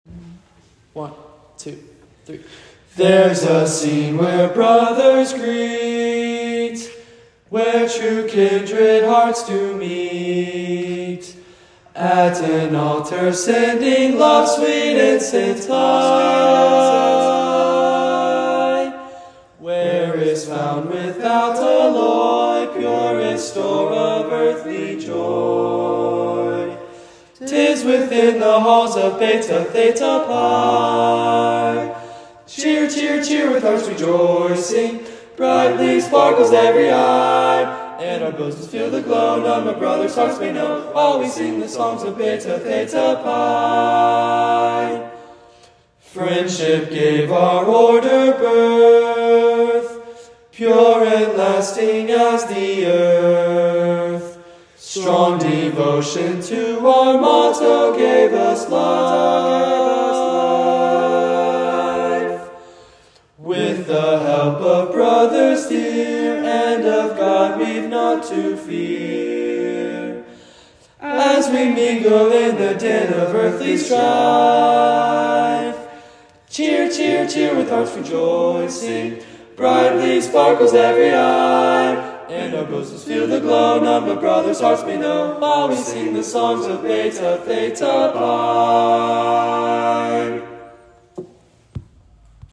Singing Awards